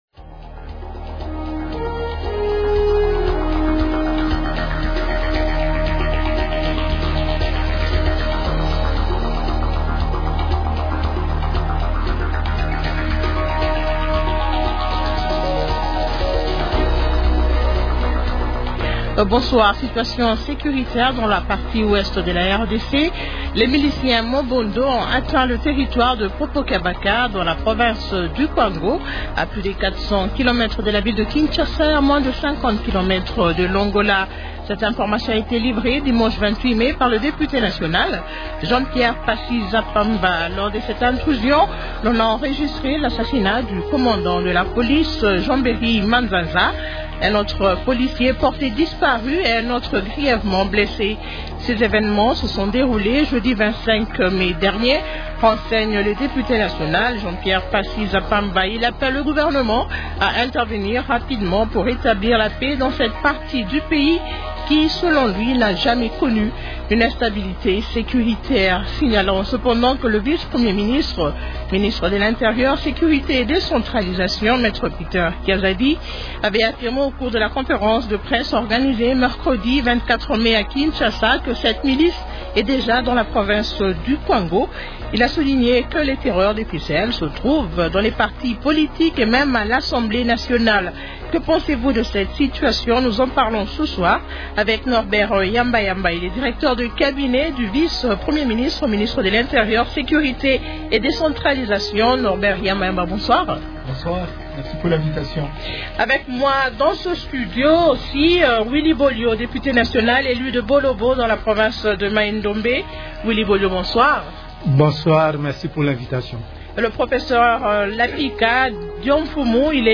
-Willy Bolio, député national élu de Bolobo dans la province du Maindombe.